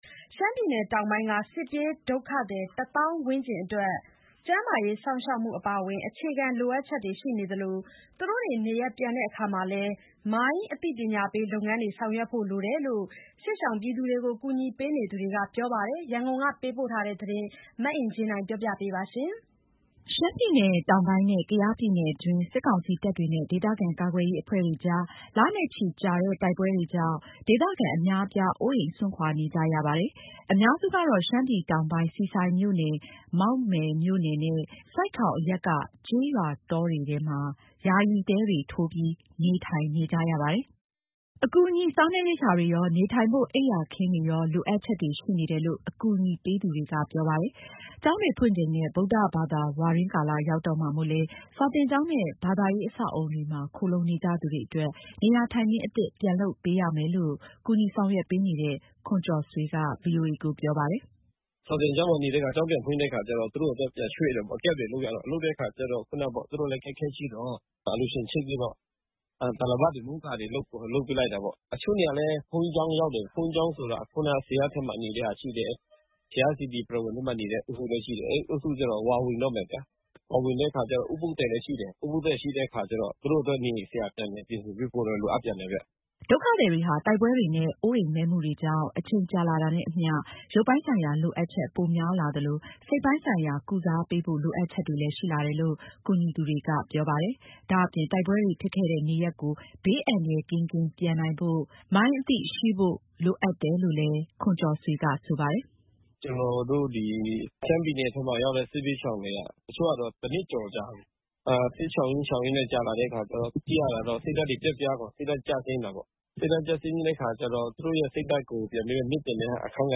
ရနျကုနျက ပေးပို့တဲ့သတငျး